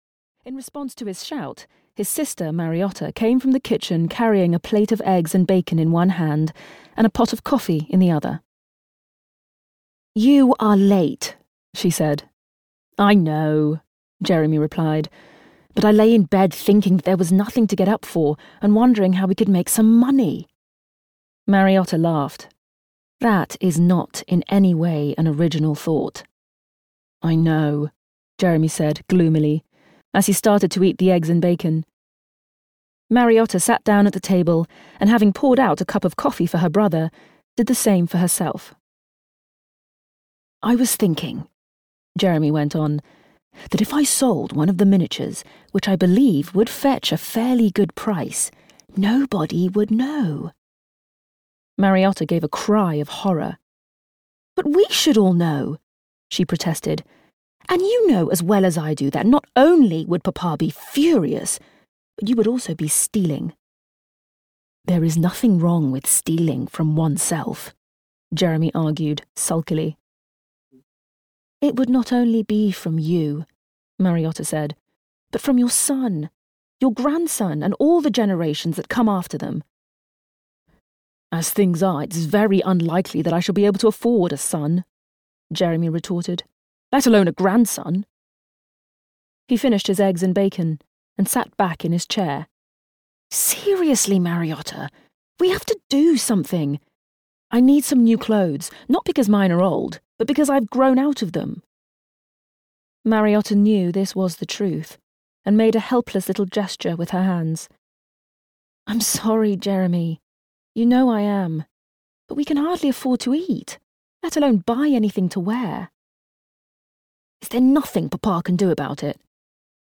Wish For Love (EN) audiokniha
Ukázka z knihy